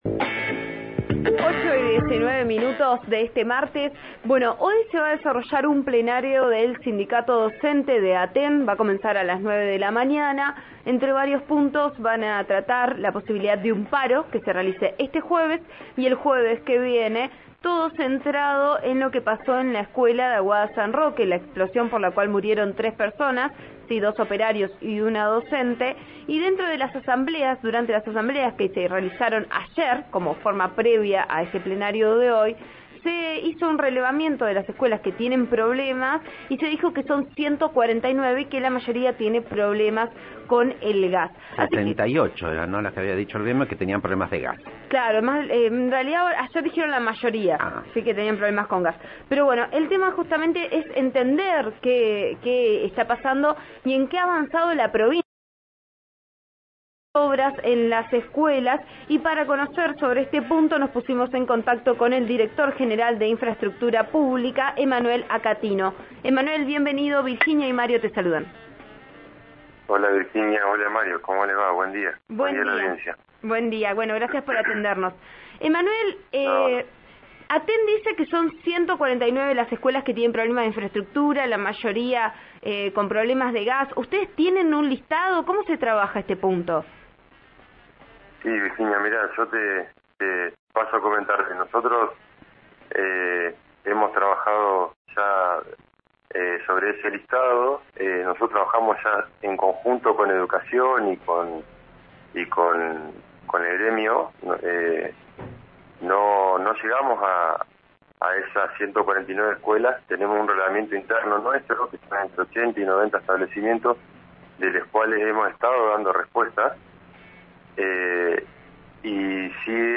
Accatino remarcó que no todos los problemas son por gas, en diálogo con «Vos A Diario» (RN RADIO 89.3).